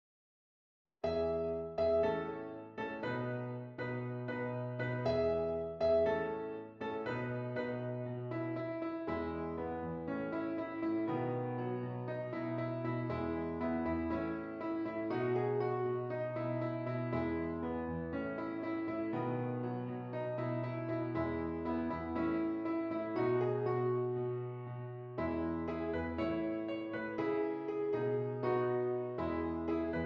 E Major
Moderately